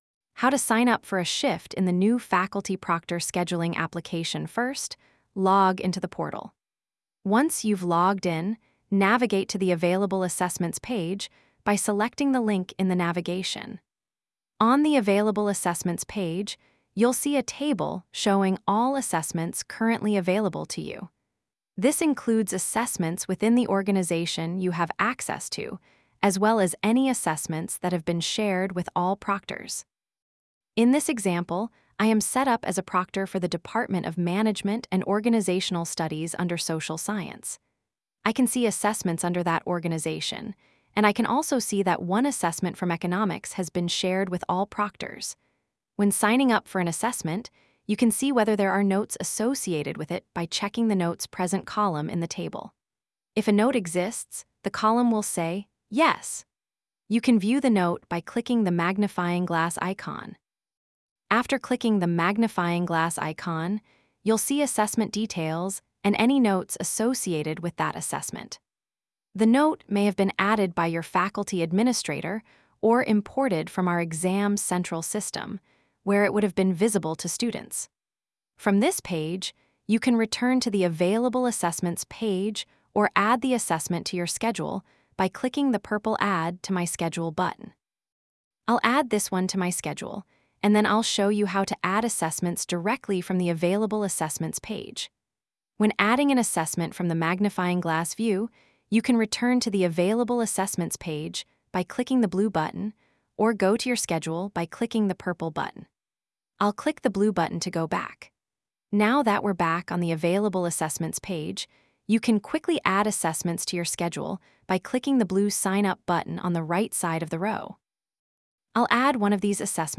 AI-TTS